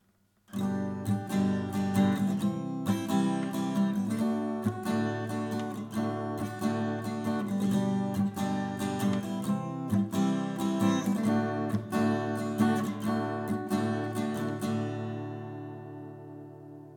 und unten kannst Du hören, wie sie mit einem einfachen Schlagmuster klingen.
I – IV – V (G-Dur)
I-IV-V-G-Dur.mp3